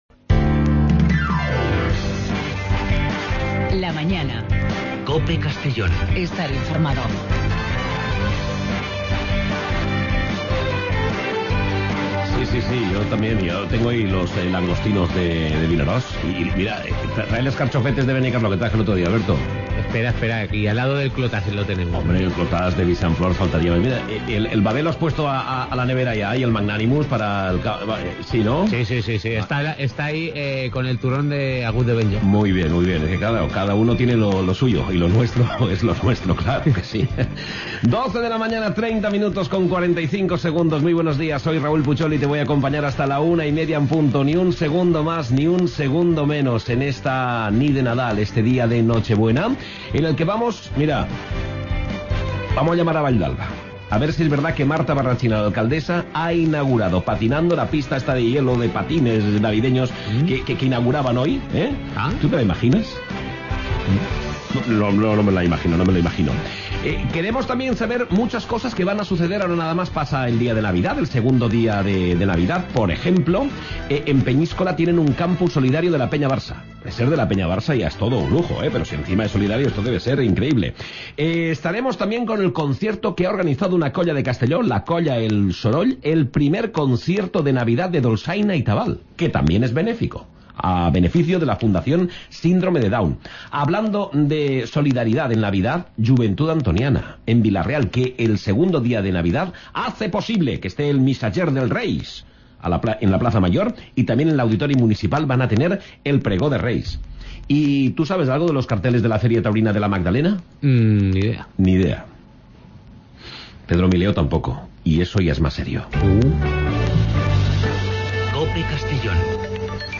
Magazine provincial